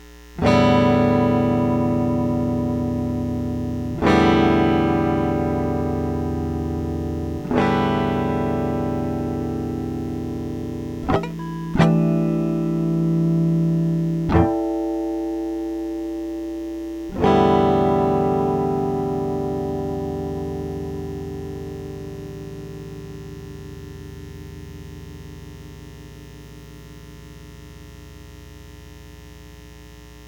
Strat, sitting in a stand, middle pickup, direct into audio interface, strum open strings.
1st sample is new cable, 2nd, old.